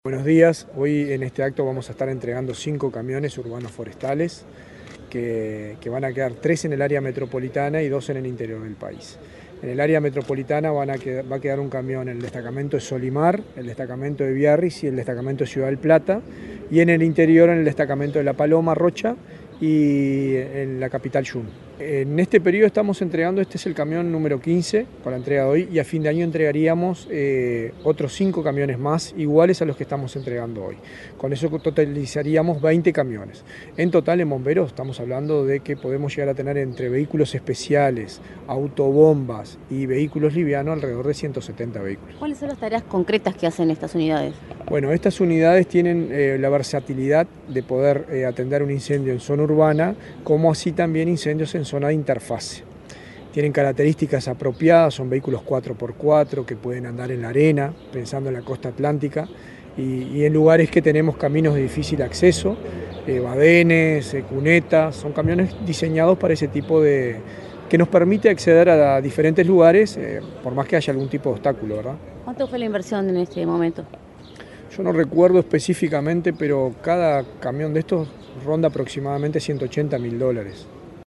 Declaraciones del director nacional de Bomberos, Richard Barboza